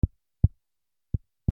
マイクをポンポンたたく(ダイナミックマイク)
/ M｜他分類 / L10 ｜電化製品・機械